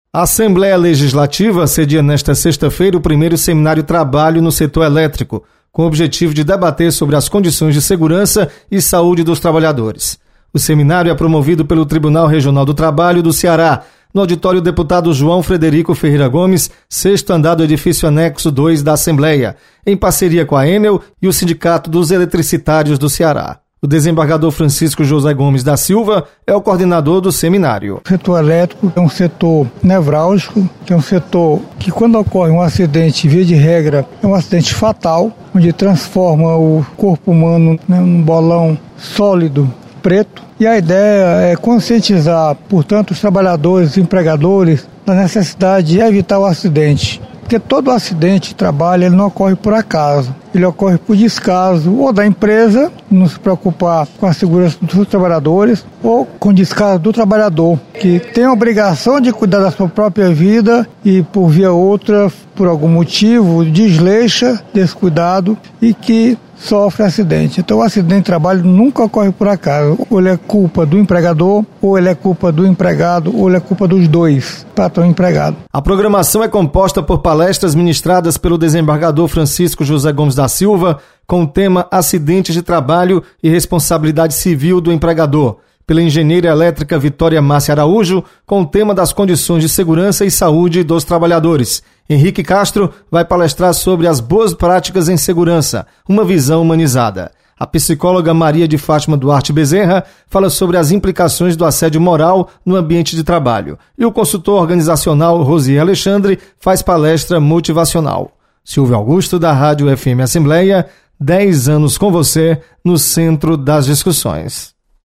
Assembleia sedia seminário sobre trabalho no setor elétrico. Repórter